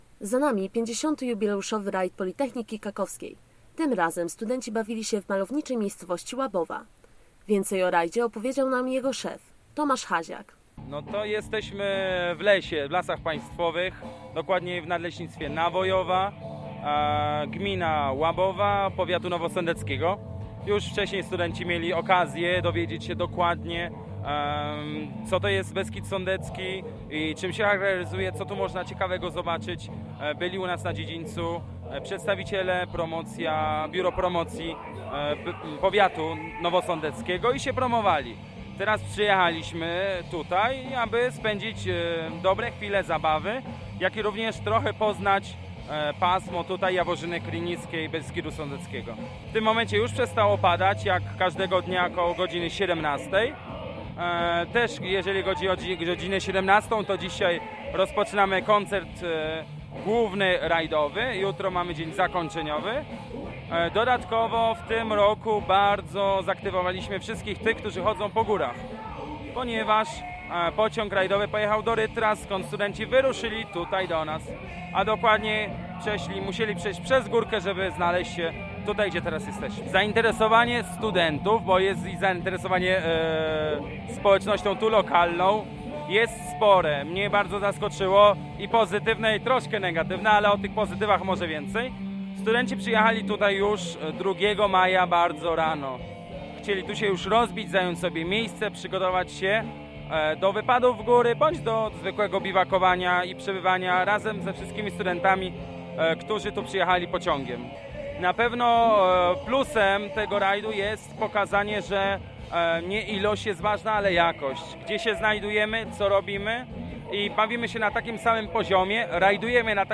Reportaż z 50. Edeńskiego Raj'du PK
Z mikrofonem Nowinek przysłuchujemy się temu co działo się w przedostatni dzień imprezy.